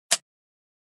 Электроприборы звуки скачать, слушать онлайн ✔в хорошем качестве